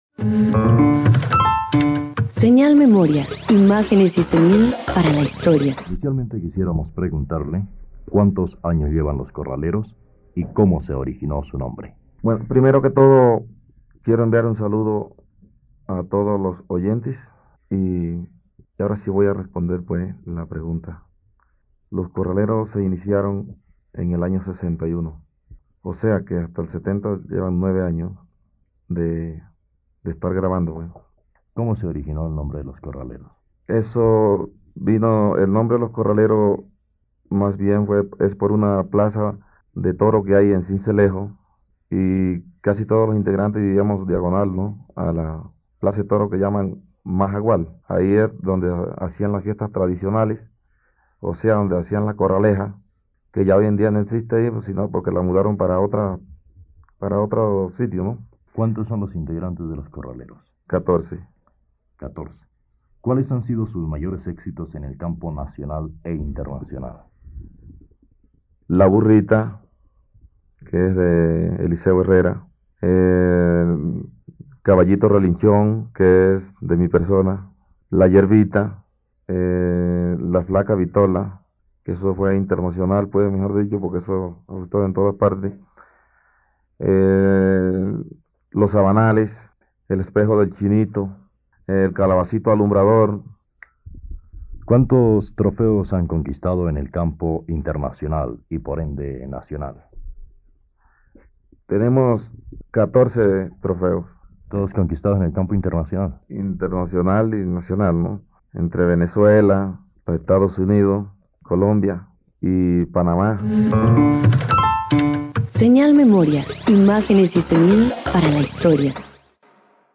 Entrevista de la emisora HJCK al músico cesarense Calixto Ochoa (1934-2015), en la cual cuenta la historia y los éxitos que hasta 1970 había cosechado el grupo Los Corraleros de Majagual, al cual pertenecía junto con otras leyendas de la música colombiana como Eliseo Herrera, Alfredo Gutiérrez y Lisandro Meza.
Programa de Entretenimiento Soporte Cinta magnética transferida a archivo digital